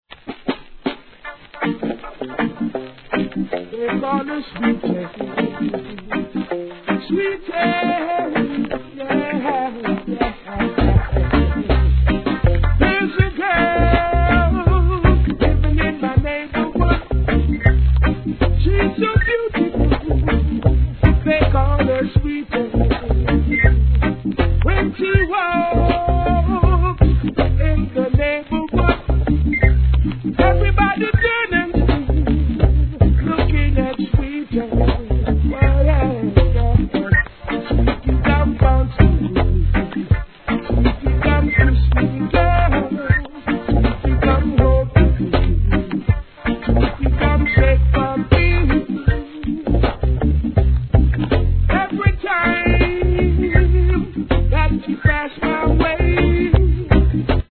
REGGAE
名曲をさすがのヴォーカルでカヴァー♪